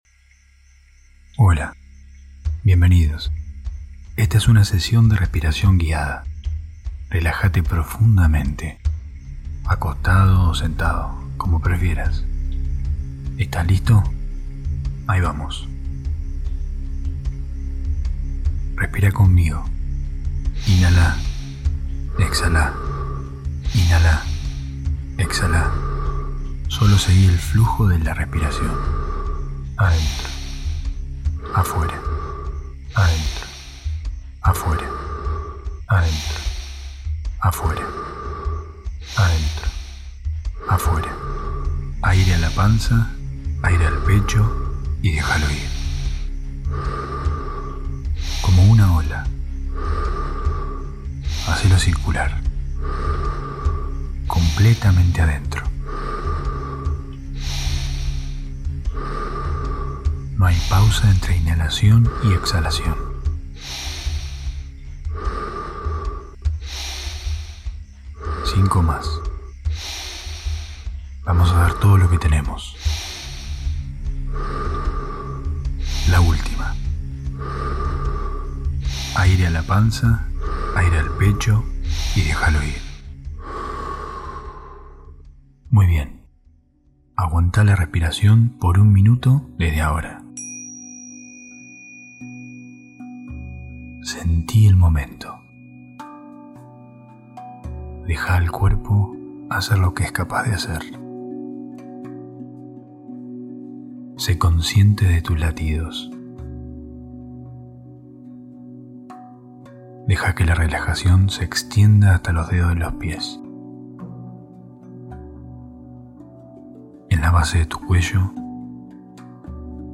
En esta técnica de respiración, se utilizan los sonidos y la conciencia corporal para reducir la ansiedad, el pánico y el estrés.